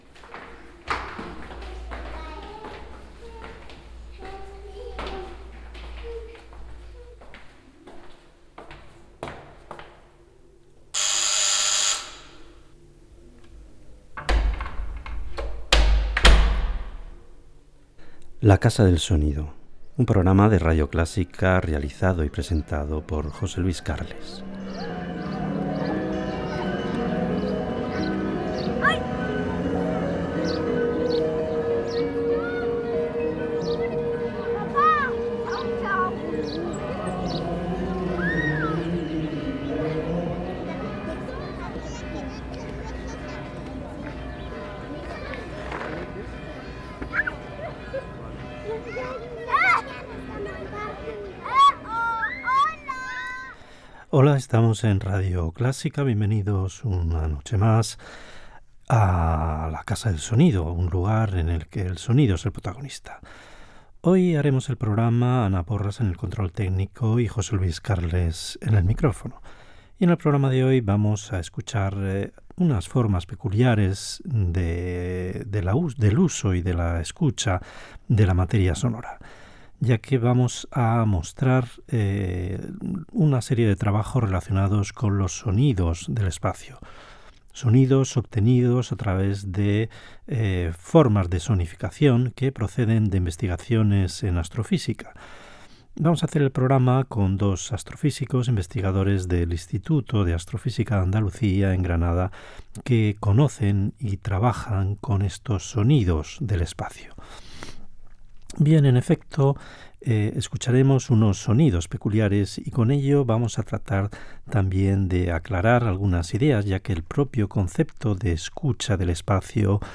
Careta, presentació del programa dedicat a la "sonificació"